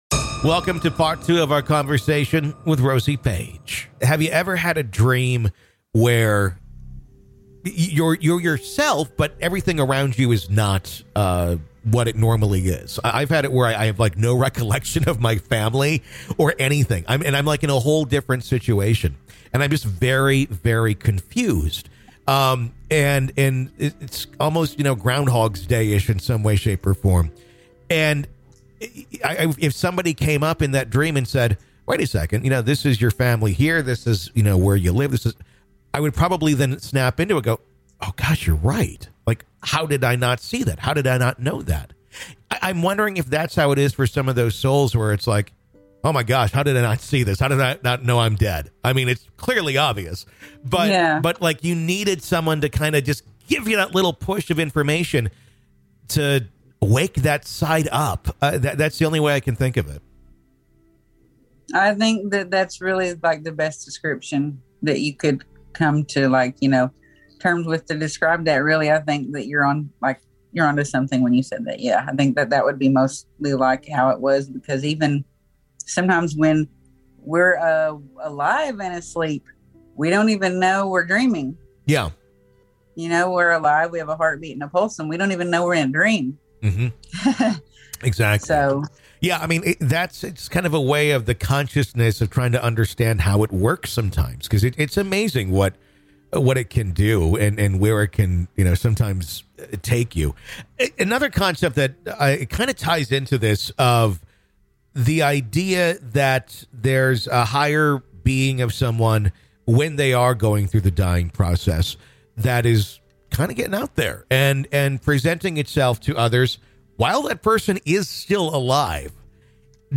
In Part Two of our conversation, we dive into how one eerie encounter kicked off a lifetime of ghostly visits.